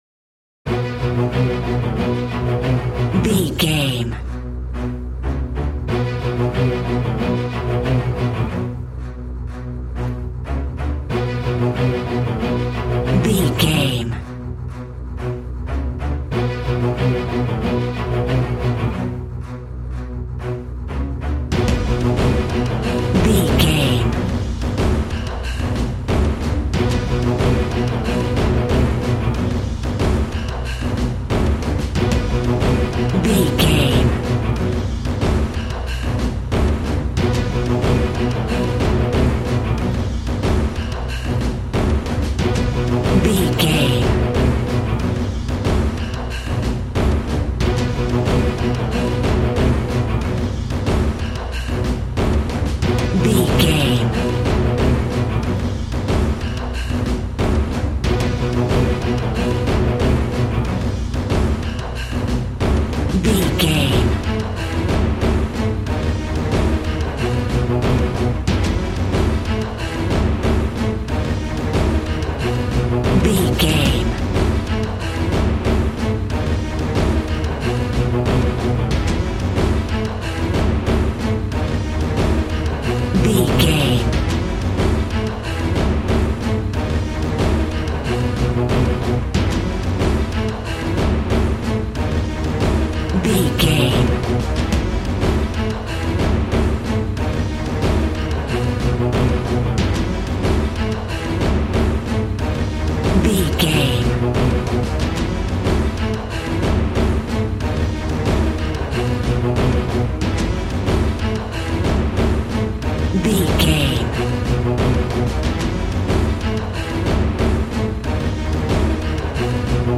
Fast paced
In-crescendo
Uplifting
Aeolian/Minor
strings
brass
percussion
synthesiser